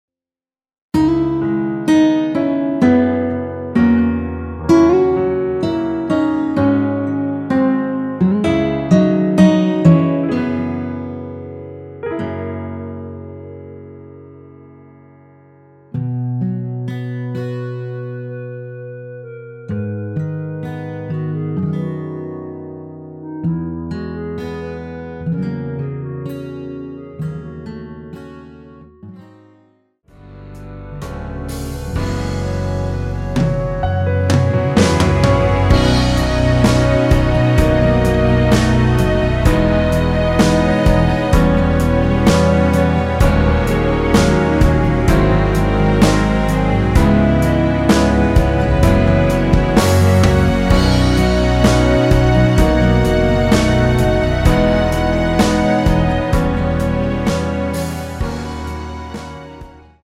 원키에서(-2)내린 멜로디 포함된 MR입니다.
앞부분30초, 뒷부분30초씩 편집해서 올려 드리고 있습니다.